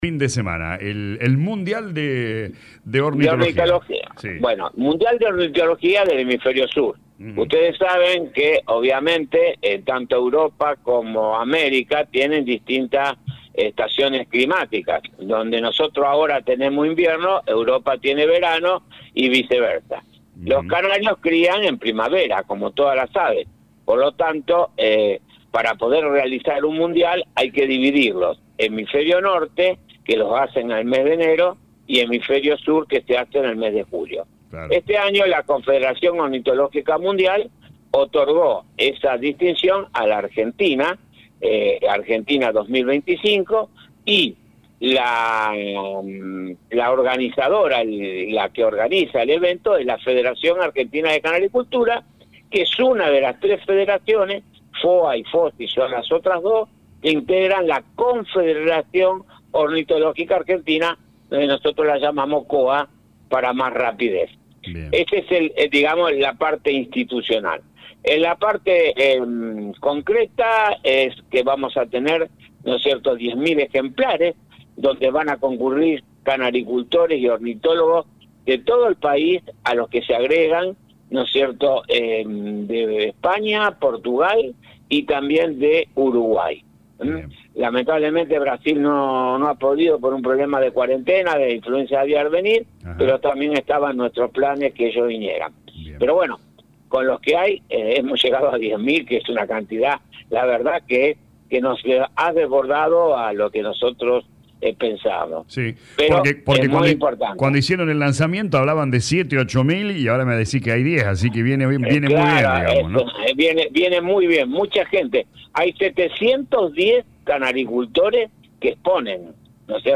habló en LA RADIO 102.9 y contó detalles del evento.